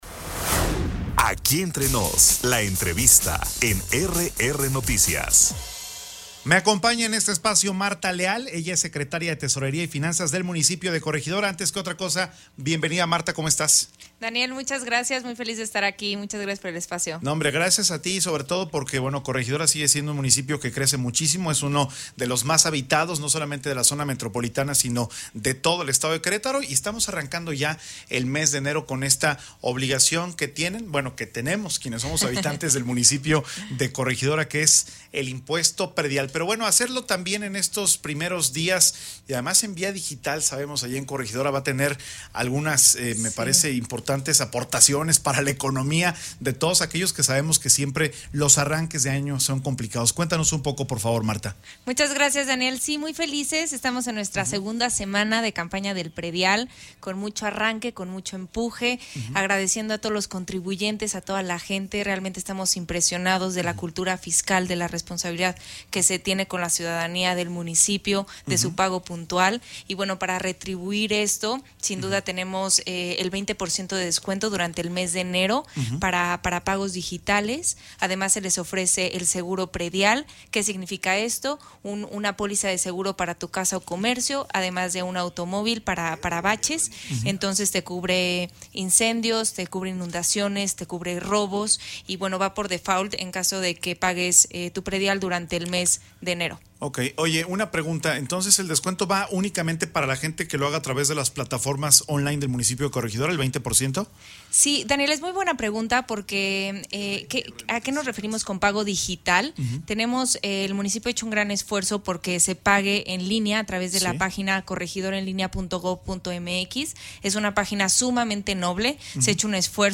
InicioEntrevistasEn primeros días de enero el 33% de contribuyentes en Corregidora ya...